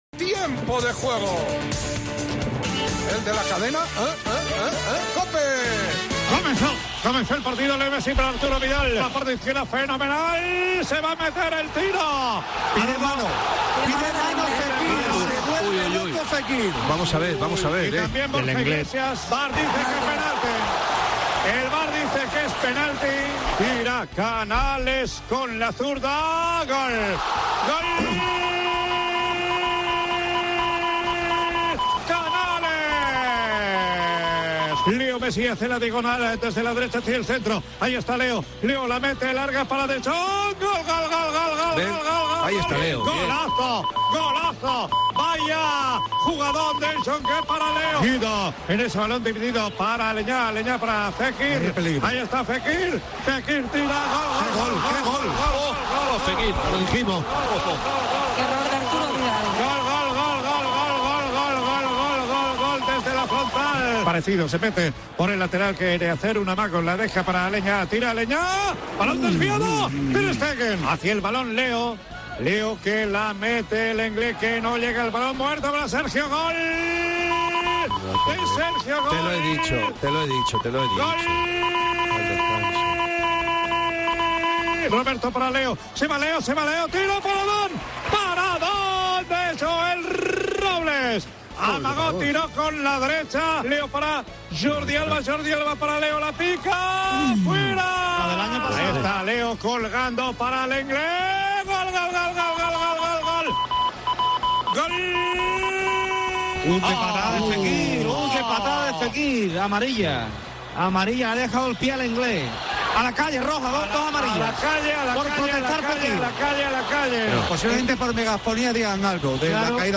Escolta els millors moments del partit amb narració